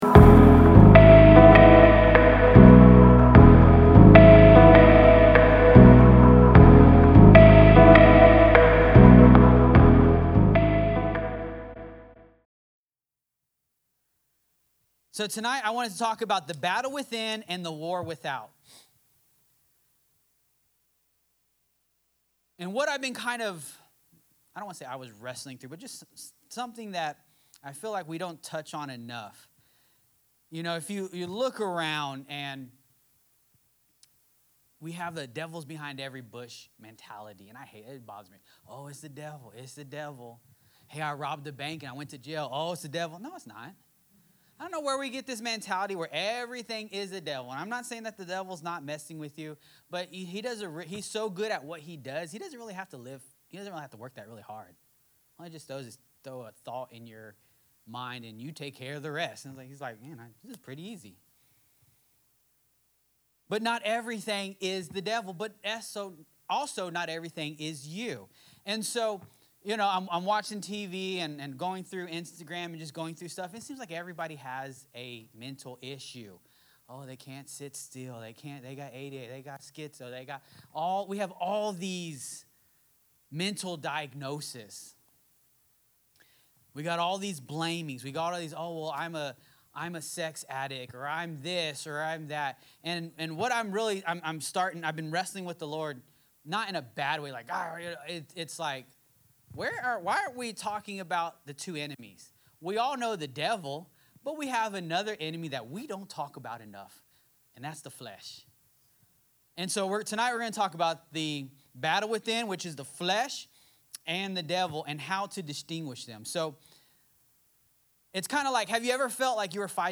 Sermons | Forerunner Church